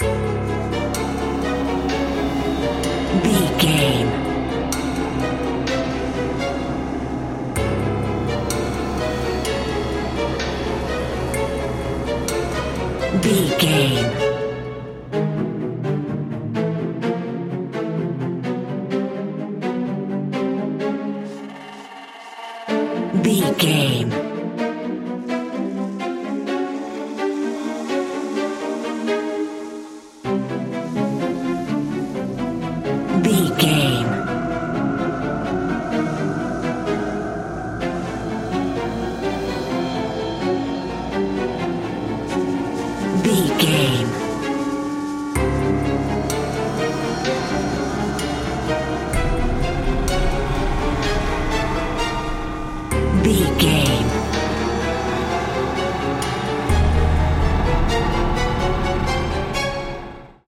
Atonal
drone
eerie
strings
synth
percussion
medium tempo
violin
drum machine